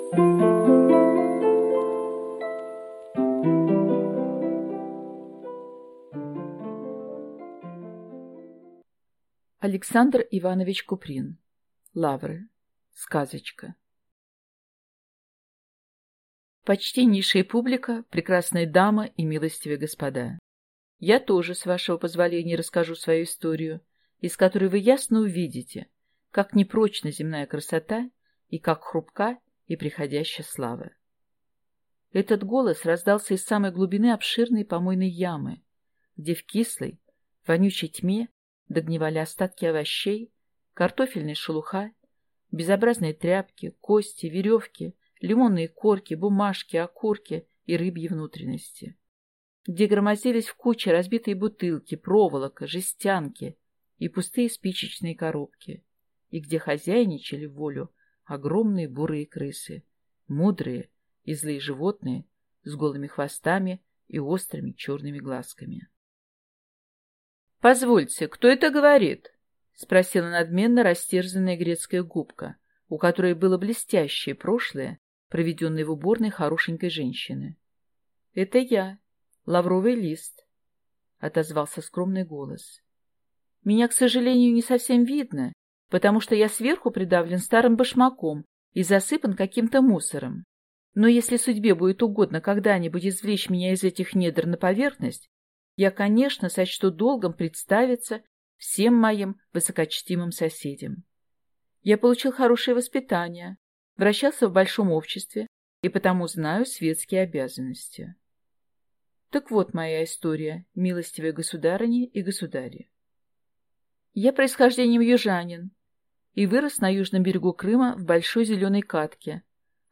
Аудиокнига Лавры | Библиотека аудиокниг